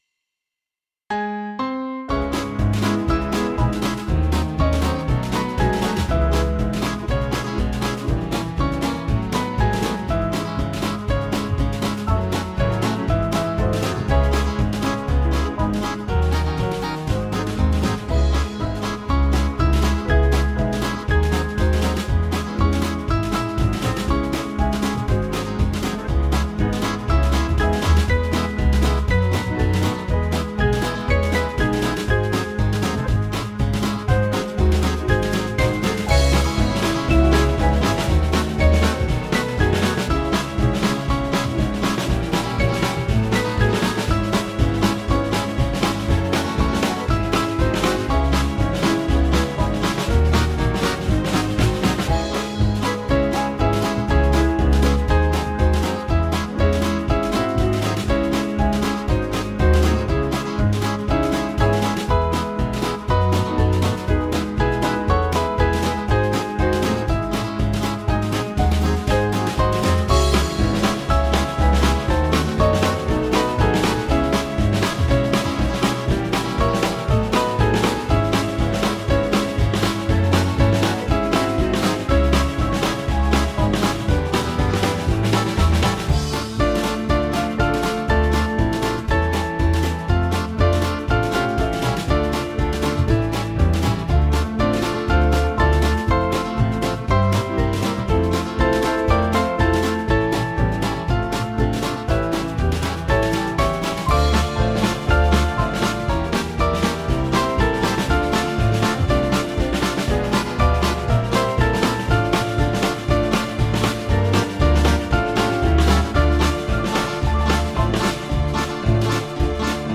African American spirtual
Small Band
(CM)   3/Dbm-Dm
Lively